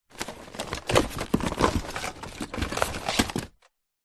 Звуки бытовые
Рыться в картонной коробке с грудой вещей